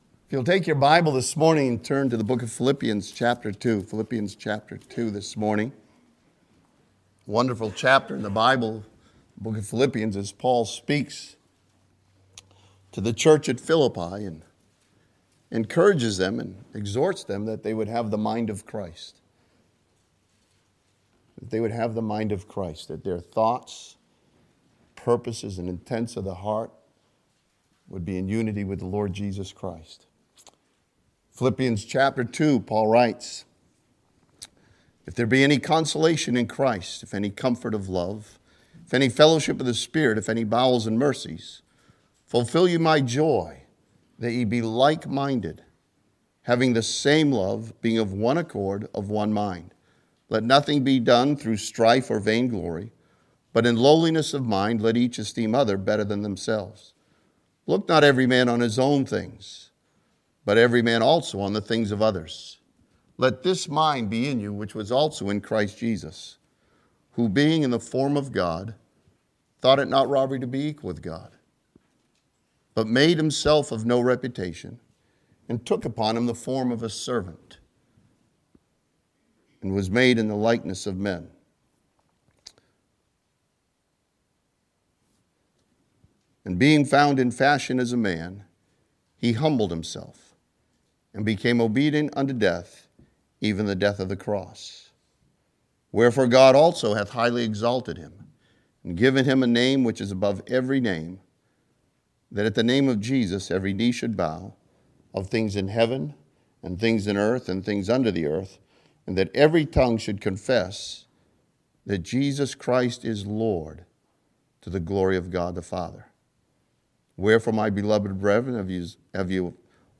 This sermon from Philippians chapter 2 studies Jesus Christ and the obedience of a servant.